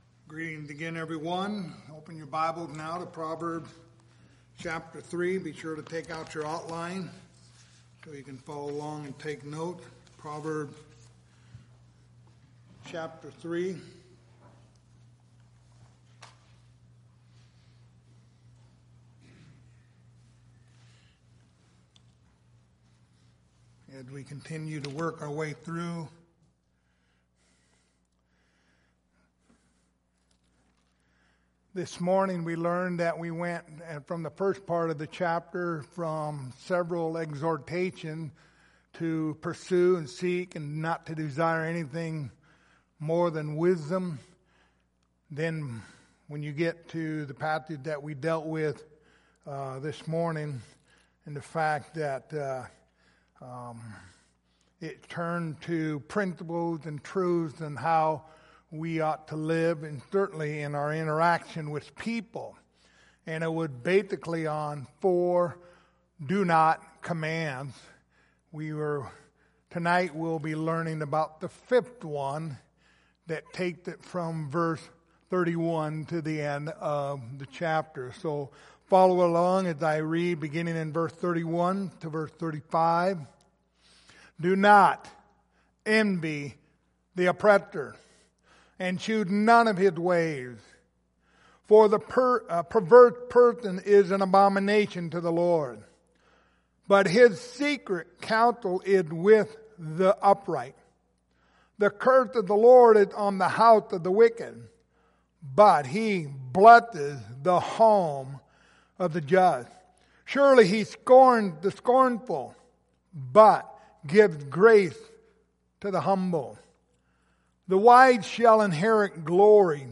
The Book of Proverbs Passage: Proverbs 3:31-35 Service Type: Sunday Evening Topics